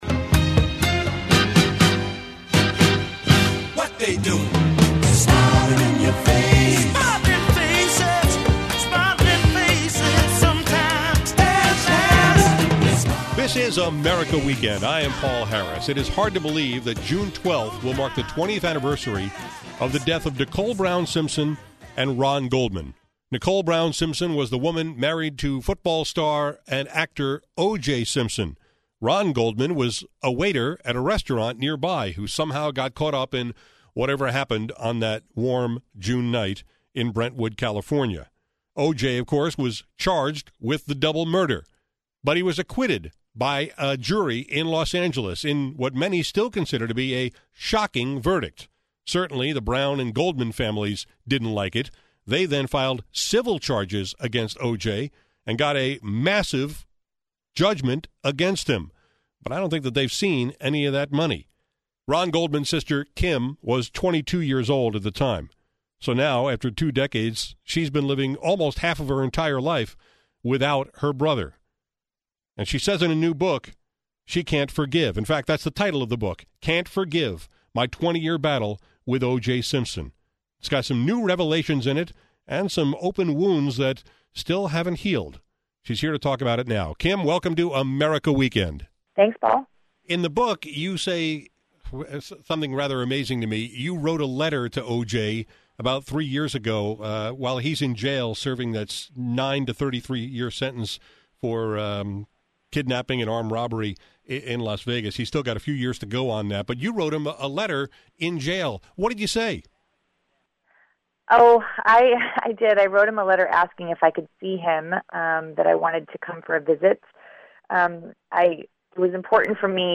As the anniversary approaches, Ron’s sister Kim — who was 22 at the time — has written a book called “Never Forgive,” which she discussed on my America Weekend show.